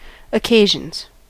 Ääntäminen
Ääntäminen US Tuntematon aksentti: IPA : /əˈkeɪ.ʒənz/ Haettu sana löytyi näillä lähdekielillä: englanti Käännöksiä ei löytynyt valitulle kohdekielelle.